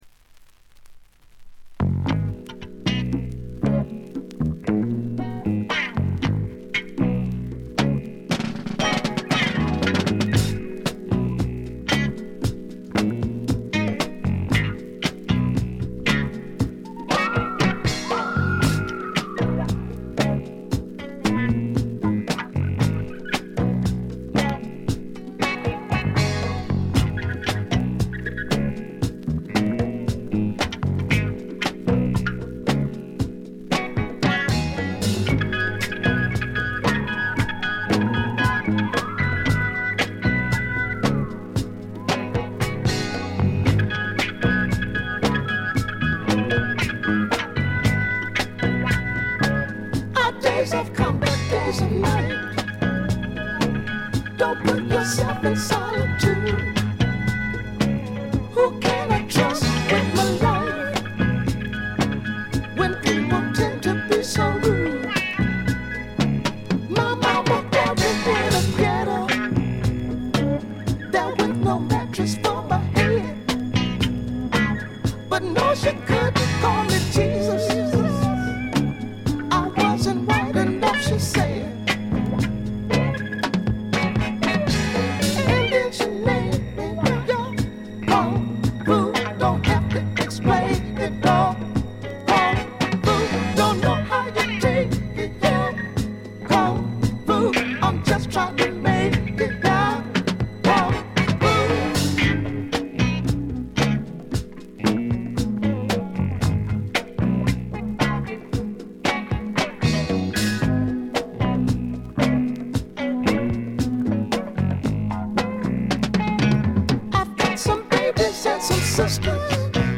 静音部での微細なバックグラウンドノイズ程度。
試聴曲は現品からの取り込み音源です。